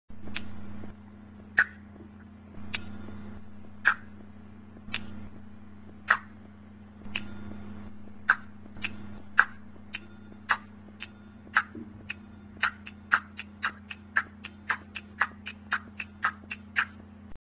The ching is a percussion instrument of the cymbal type.
The two ching are played by hitting them together.
ching.mp3